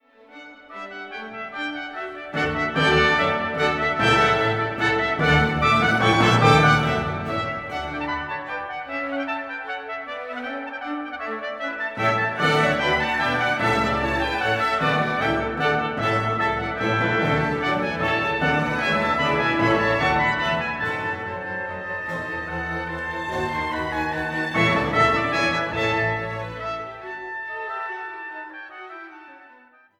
erklangen in Festgottesdiensten